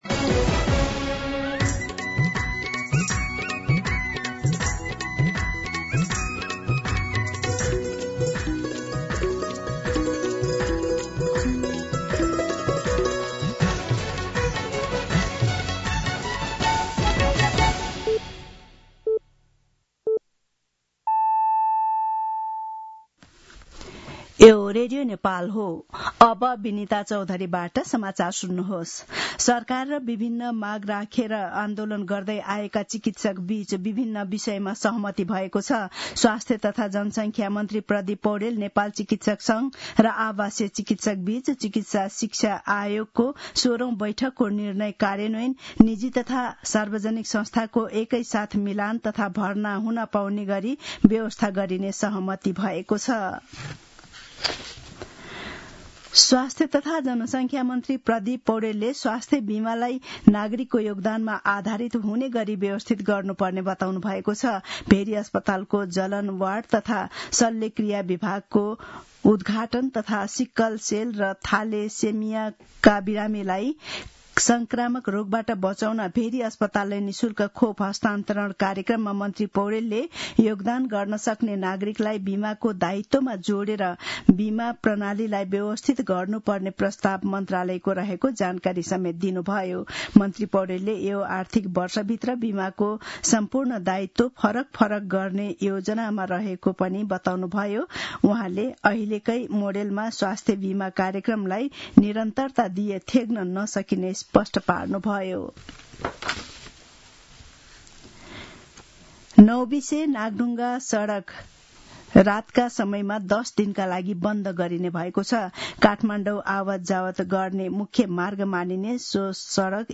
An online outlet of Nepal's national radio broadcaster
मध्यान्ह १२ बजेको नेपाली समाचार : २१ वैशाख , २०८२
12-pm-Nepali-News.mp3